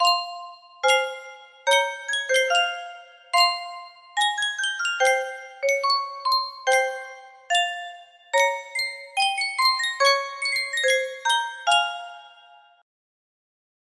Sankyo Music Box - Antonin Dvorak チェロ協奏曲ロ短調 FWT
Full range 60